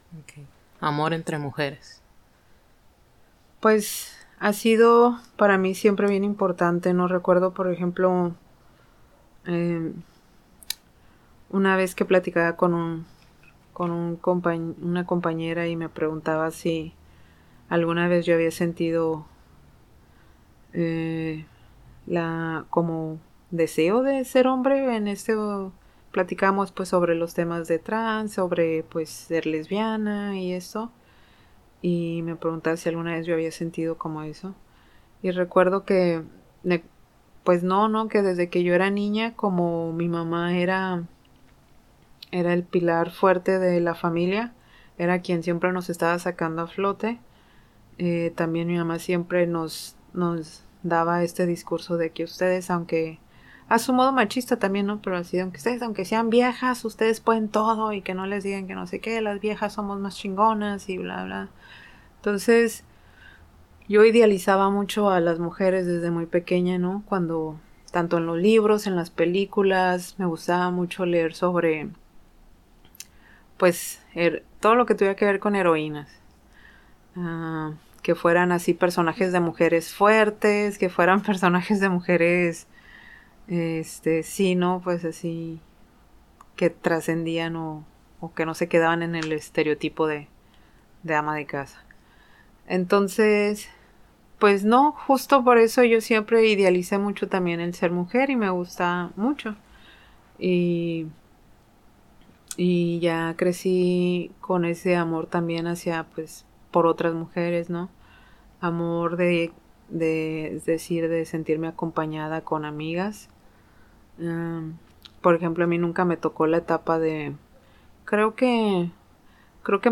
Segunda parte de entrevista